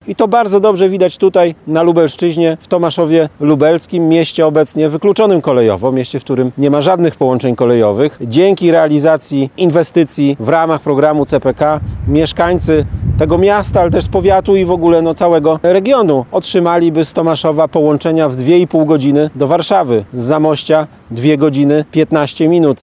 Wykluczenie komunikacyjne i Centralny Port Komunikacyjny były tematem konferencji prasowej zorganizowanej dziś przed starostwem w Tomaszowie Lubelskim.